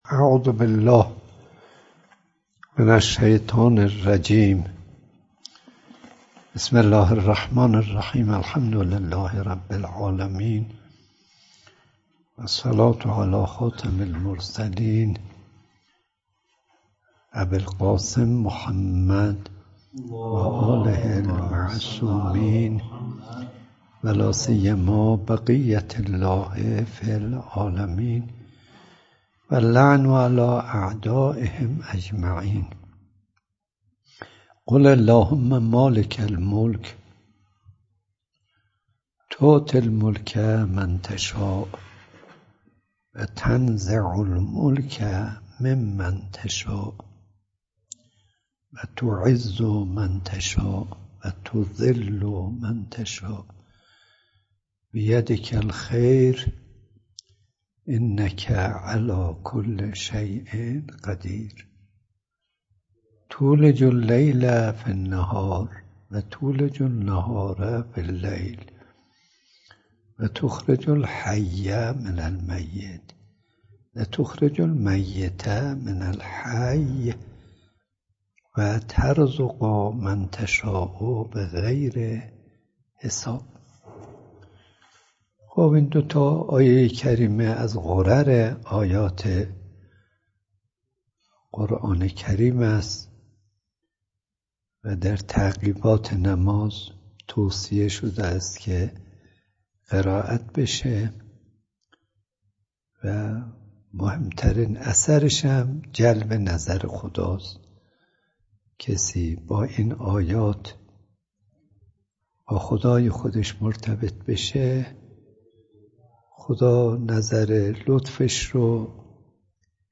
آیت الله صدیقی حریم ملکوت ۲۱۰ درس اخلاق آیت الله صدیقی؛ ۰۳ اردیبهشت ۱۴۰۳ در حال لود شدن فایل های صوتی...